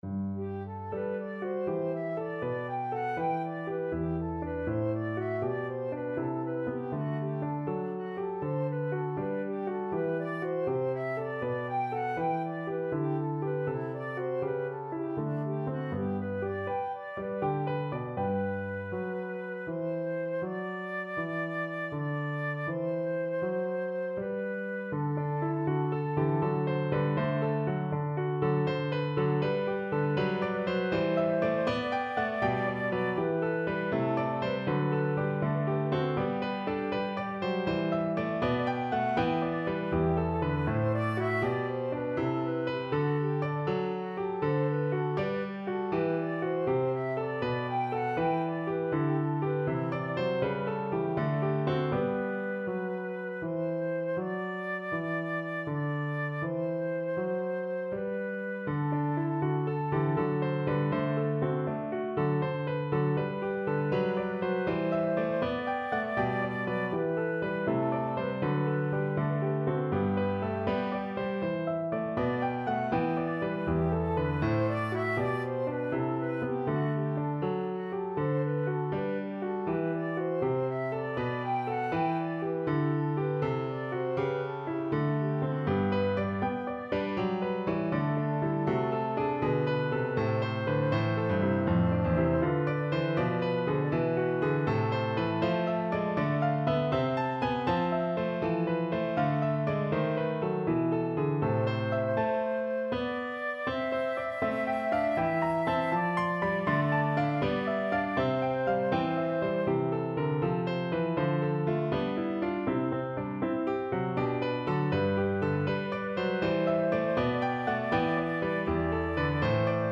Flute
Piano accompaniment